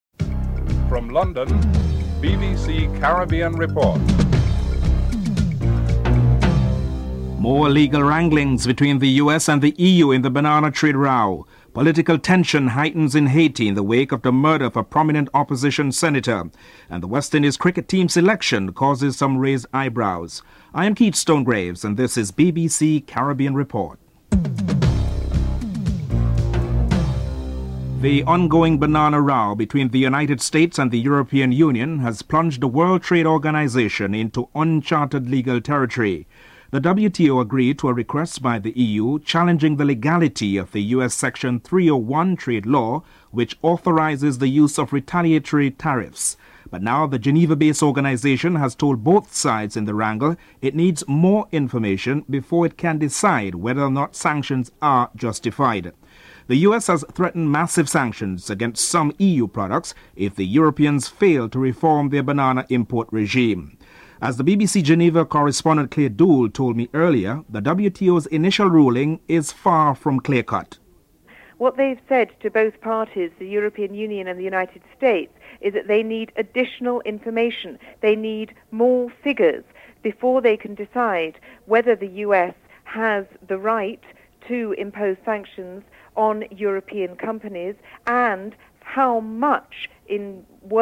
3. Saint Lucia Tourism Minister Philip J. Pierre discusses the unwillingness of the country to pay a 1.5 million dollar subsidy to American Airlines for a second consecutive year.